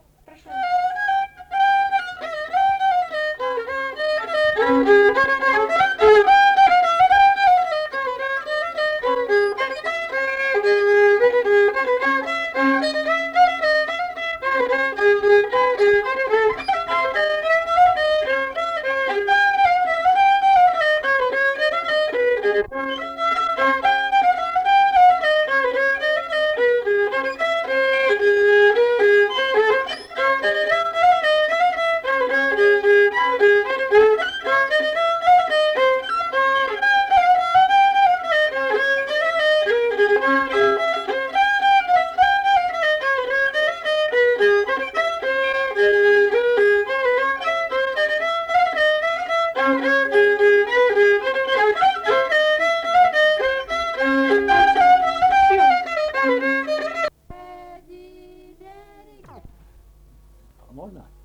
Tipas daina Erdvinė aprėptis Degučiai I (Rokiškis)
Atlikimo pubūdis vokalinis